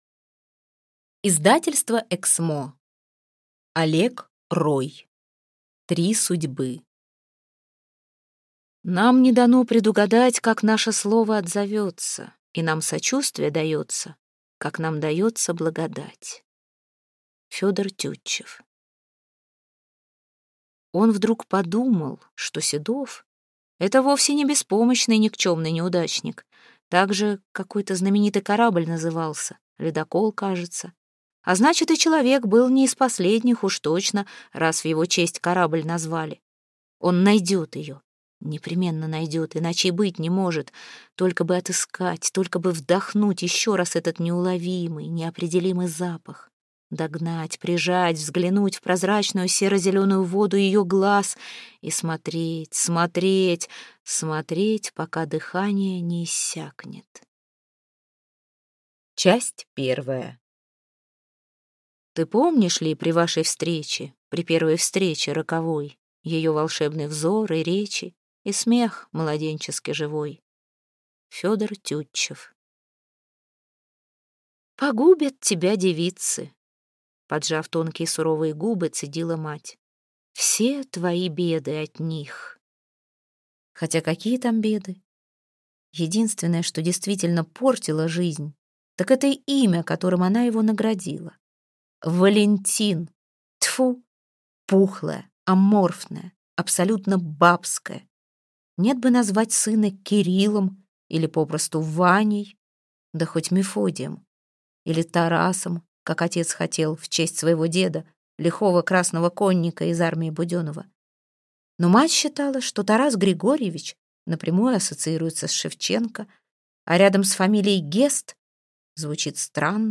Аудиокнига Три судьбы - купить, скачать и слушать онлайн | КнигоПоиск